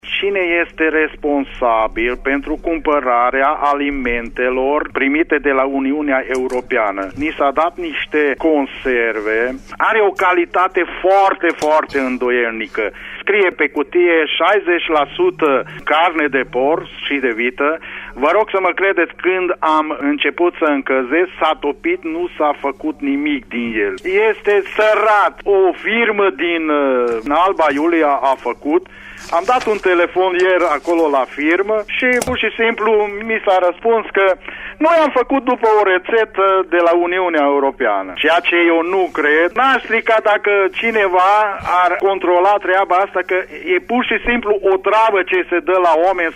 27-ian-ascultator-Parerea-ta-ajutoare.mp3